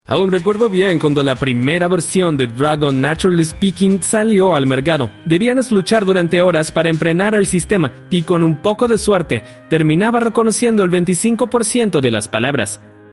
Demo de Bark, voz random